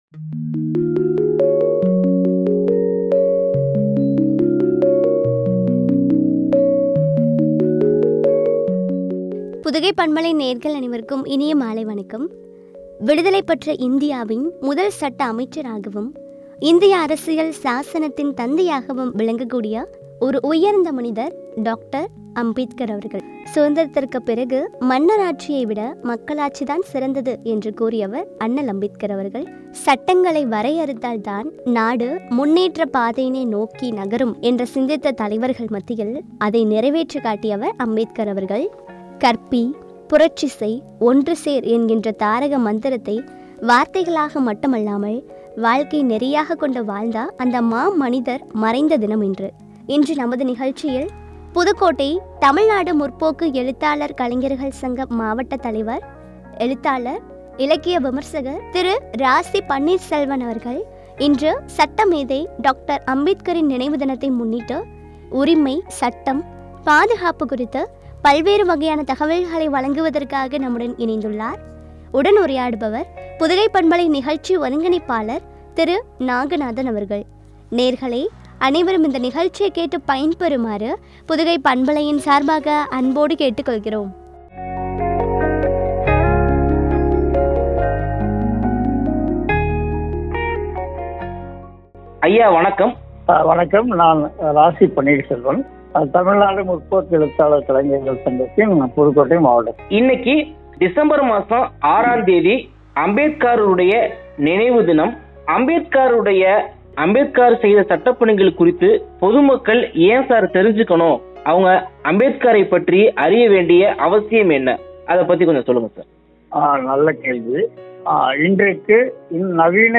உரிமை – சட்டம் – பாதுகாப்பு குறித்து வழங்கிய உரையாடல்.